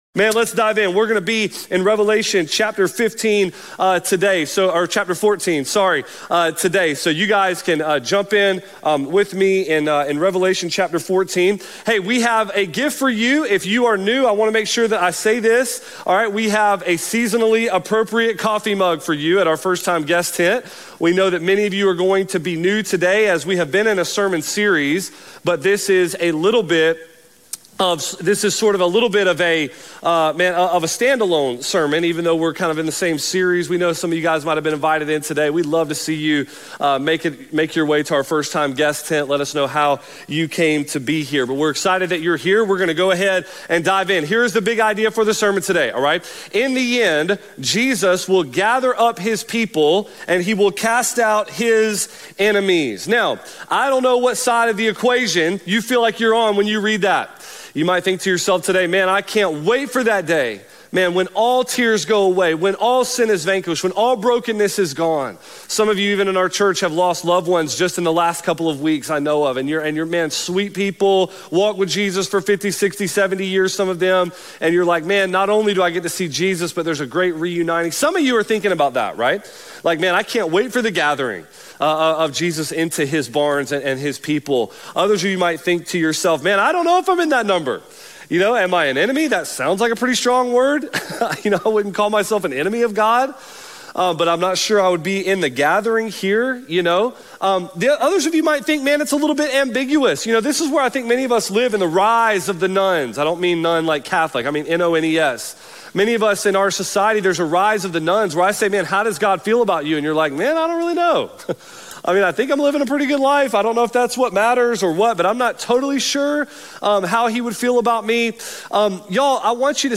Mercy Hill Church